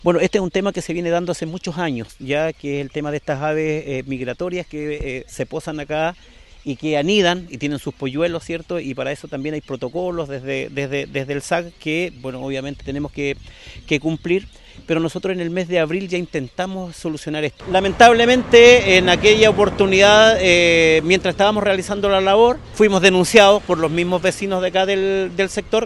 Al lugar de la protesta llegó el alcalde de Los Lagos, Víctor Fritz, quien aseguró que meses atrás intentaron talar los árboles para terminar con estos problemas, pero fueron denunciados por vecinos.
cu-pajaros-4-alcalde.mp3